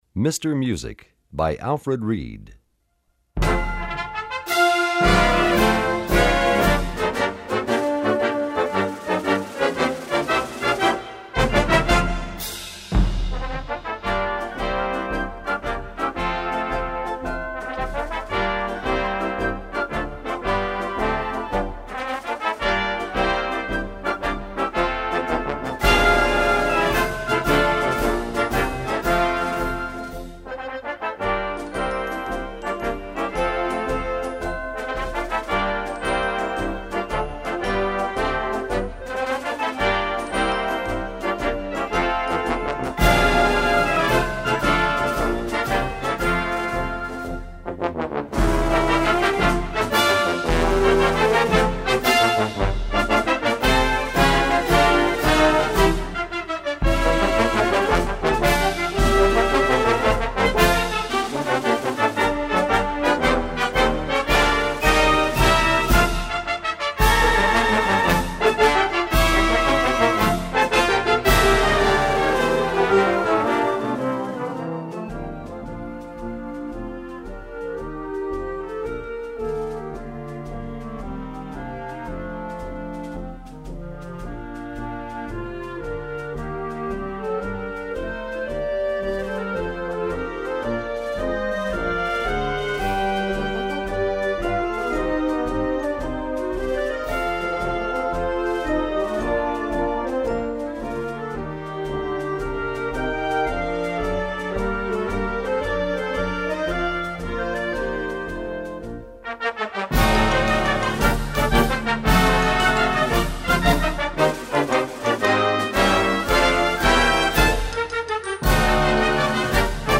Gattung: Konzertmarsch
Besetzung: Blasorchester
A scintillating concert march for band